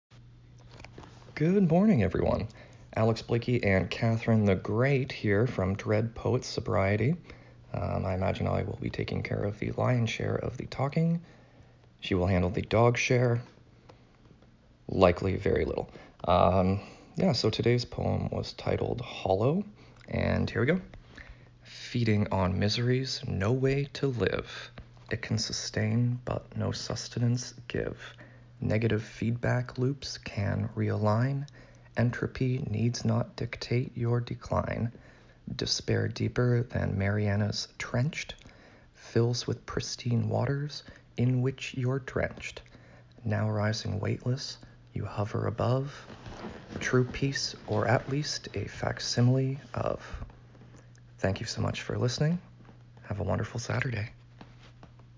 I will include the audio of the poem again below plus a little extra chatter for those of you dedicated enough to unmute your devices.